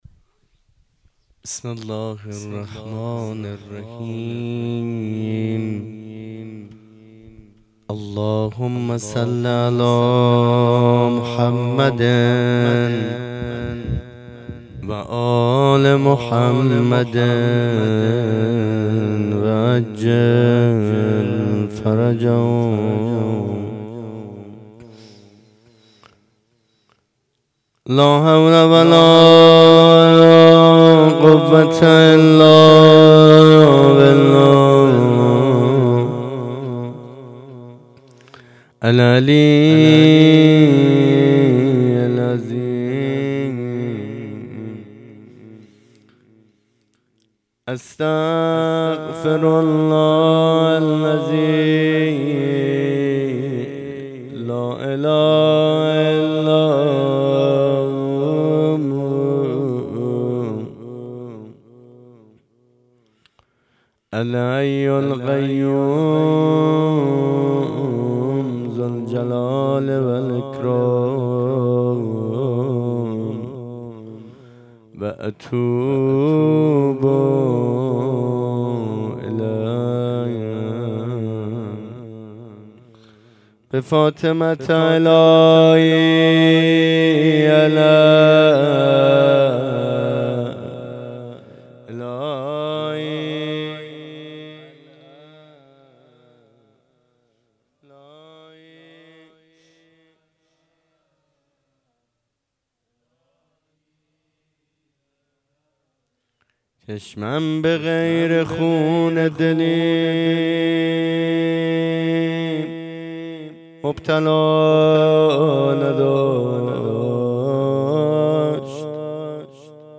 ویژه برنامه هفتگی هیئت در ماه مبارک رمضان-وفات حضرت خدیجه1403